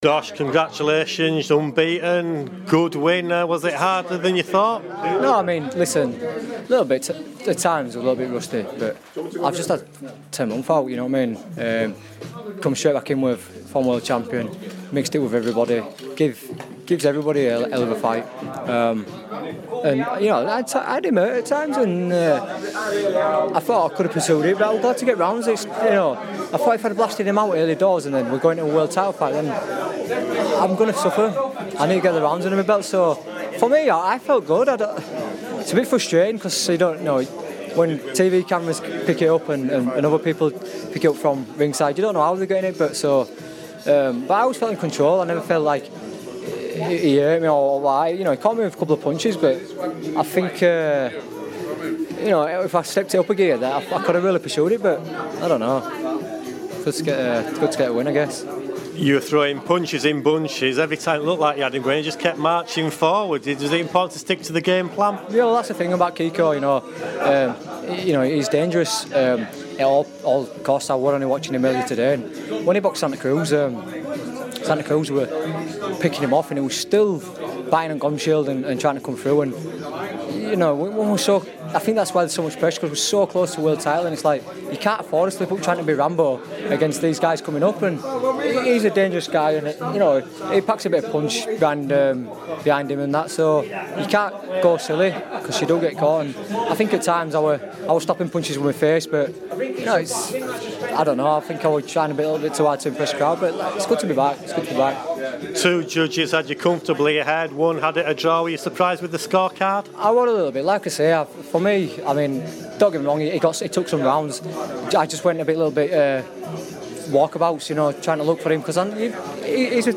Josh Warrington speaks to Radio Yorkshire after securing victory over Kiko Martinez at the First Direct Arena in Leeds.